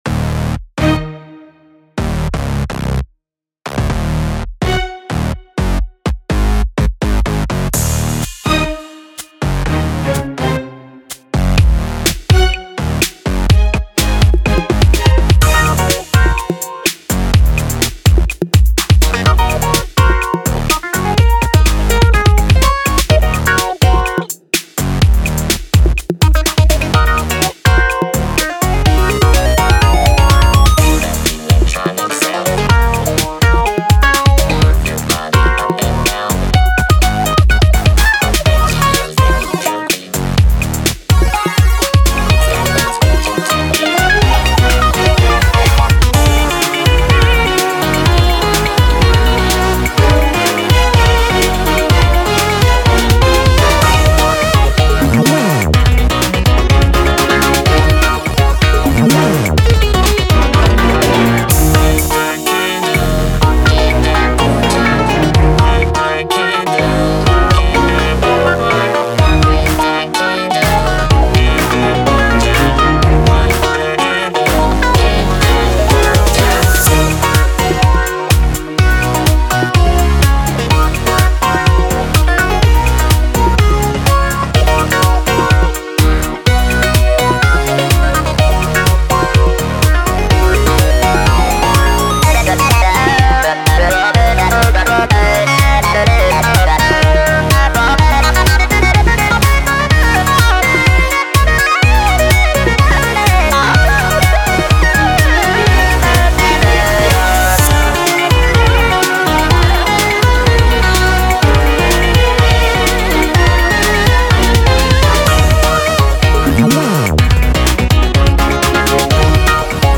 BPM125
MP3 QualityMusic Cut